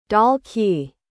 '달기Dalgi’ [Pronounced: Dal-Ki]
dalgi_pronunciation.mp3